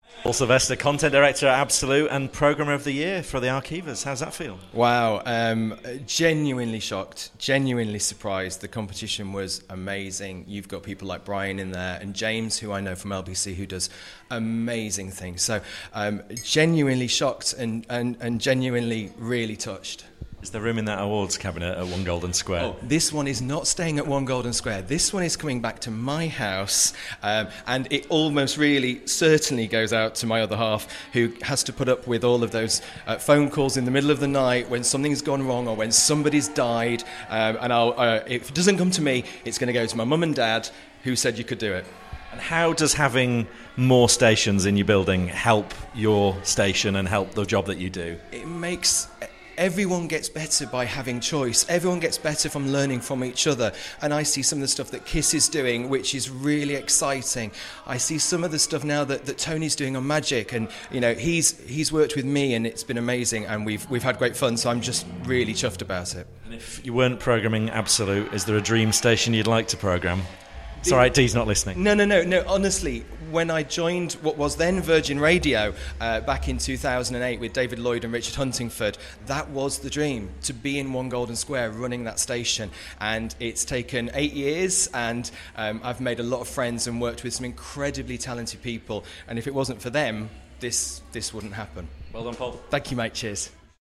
RadioToday Live Interviews
backstage